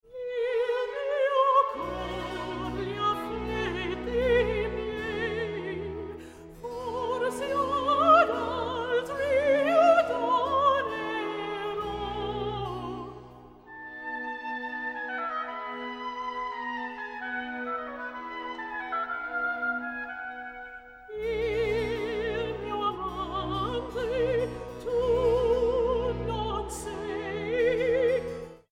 Vocal treasures of the 18th & 19th centuries
Soprano